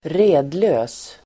redlös adjektiv (om människor " starkt berusad"), disabled [of people " blind drunk"]Uttal: [²r'e:dlö:s] Böjningar: redlöst, redlösaSynonymer: stupfullDefinition: omöjlig att styra